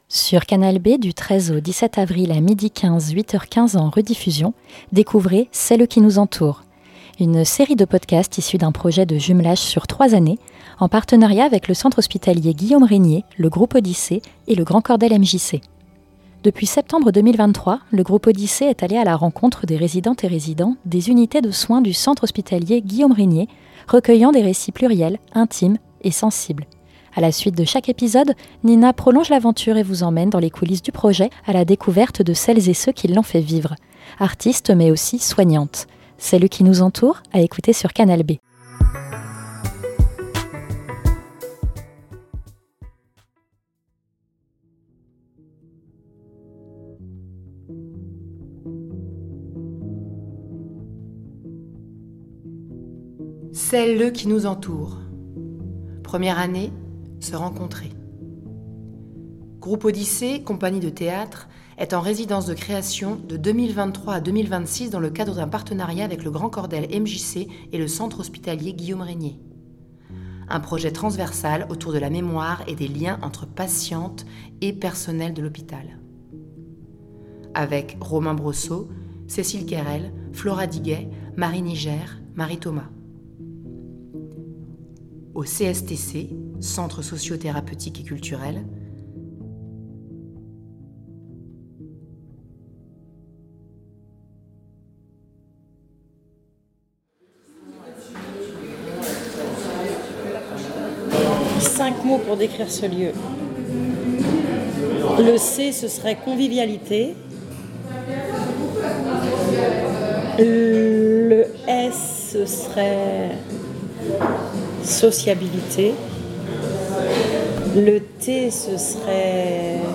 Restitution sonore
C'est au centre socio-thérapeutique et culturel de Rennes que ce projet pose ses valises pour le premier épisode d'une série de cinq restitutions sonores. Interview A la suite de chaque épisode